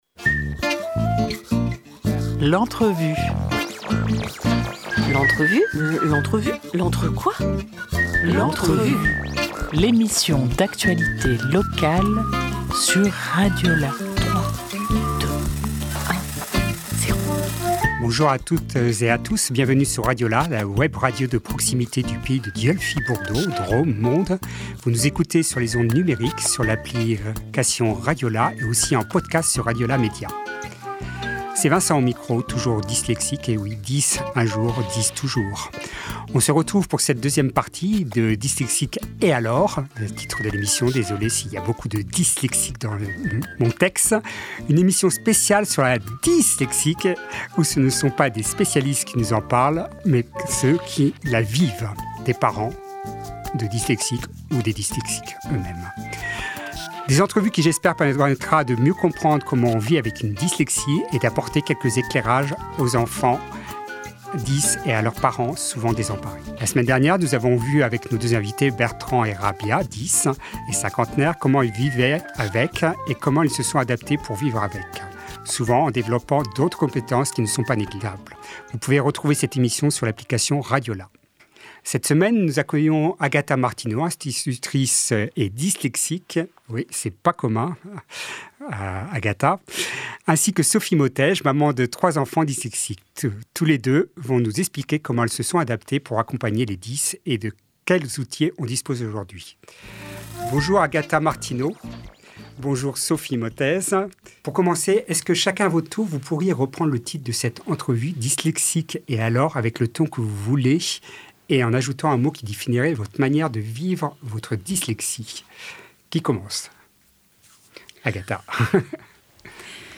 1 décembre 2025 11:17 | Interview